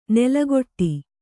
♪ nelagoṭṭi